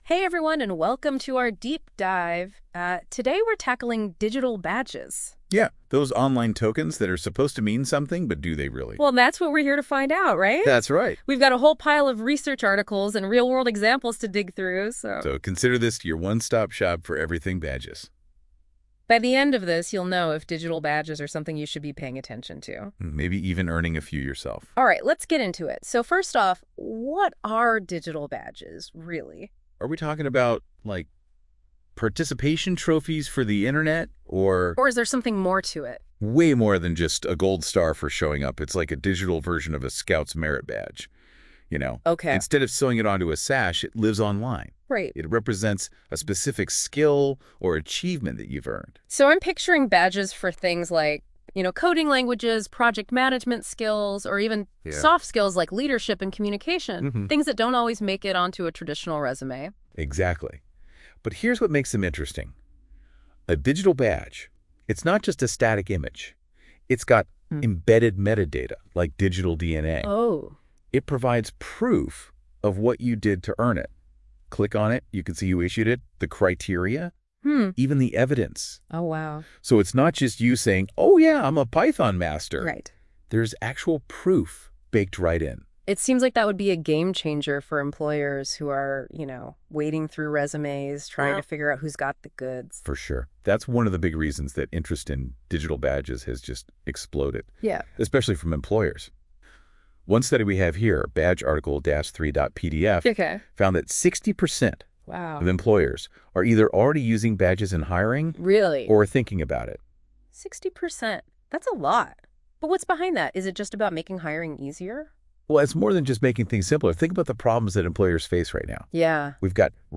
If you don’t want to wait for NotebookLM to create the podcast you can listen to the version it created for the DSC: Podcast Summary Audio (12-min).